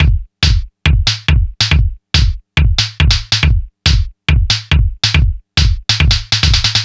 NRG 4 On The Floor 044.wav